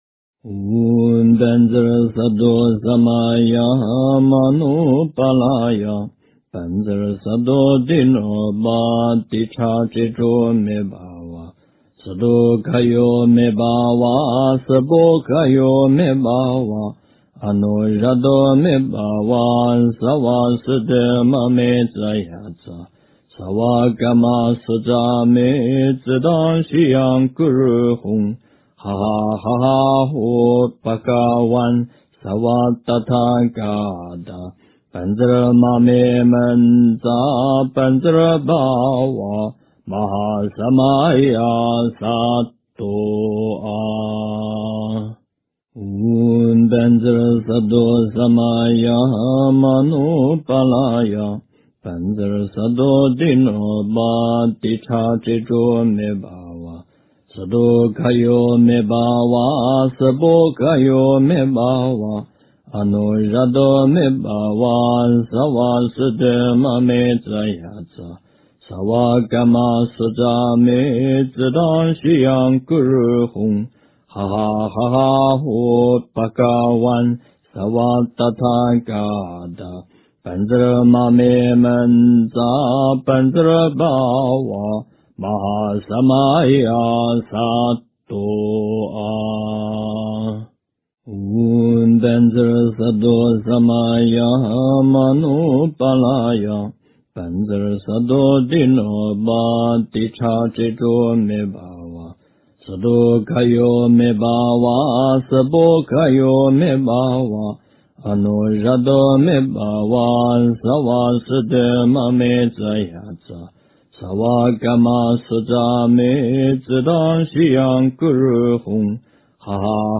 经忏
佛音 经忏 佛教音乐 返回列表 上一篇： 南无阿弥陀佛--印光大师版 下一篇： 忏悔文--女声 相关文章 南无月光菩萨圣号--缘聚禅儿童音 南无月光菩萨圣号--缘聚禅儿童音...